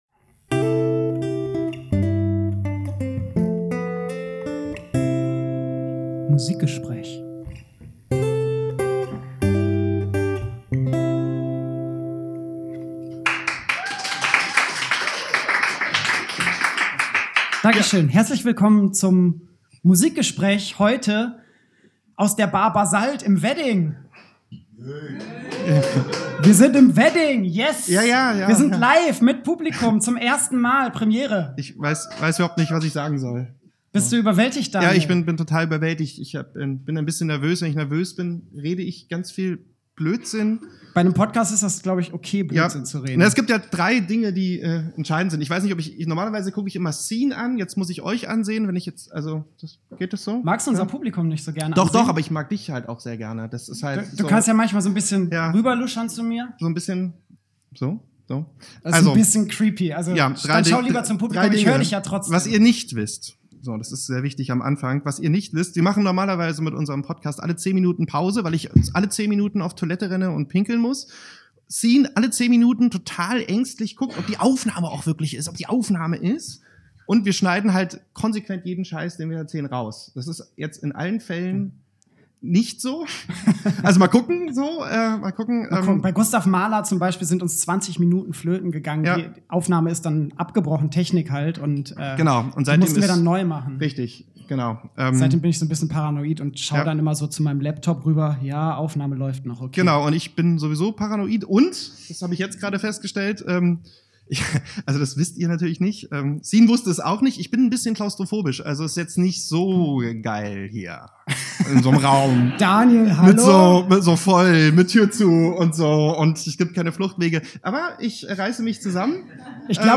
Live aus der Bar Basalt im Berliner Wedding: das Musikgespräch über Musik und Bier!